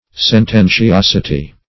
Search Result for " sententiosity" : The Collaborative International Dictionary of English v.0.48: Sententiosity \Sen*ten`ti*os"i*ty\, n. The quality or state of being sententious.
sententiosity.mp3